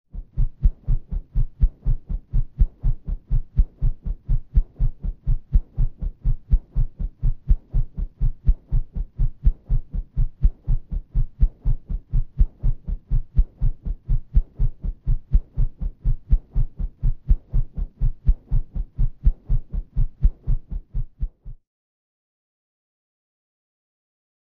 FastMultipleBladeW PE361501
Fast Multiple Blade Whooshes From Close Perspective